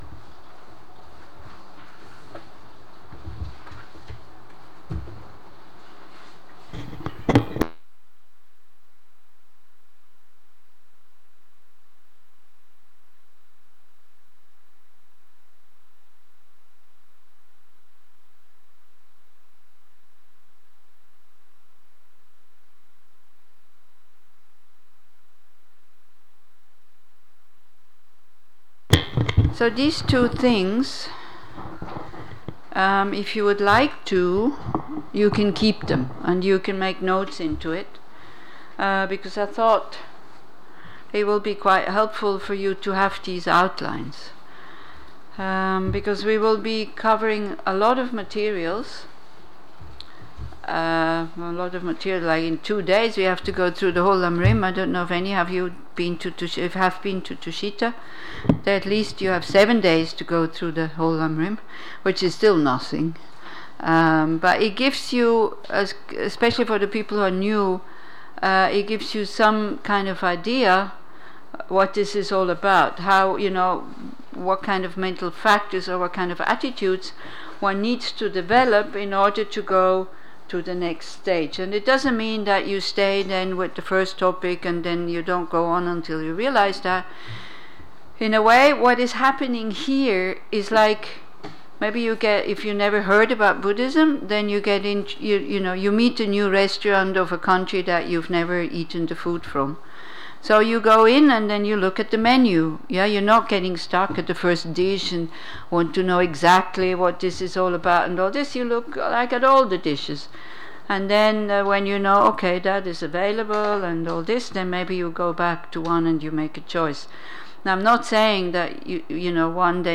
לימוד ומדיטציות